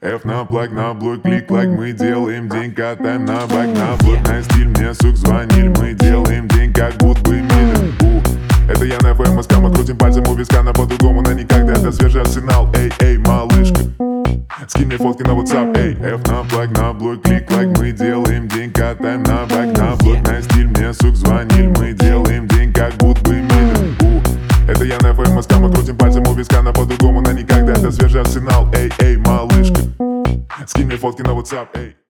Рэп и Хип Хоп
громкие # грустные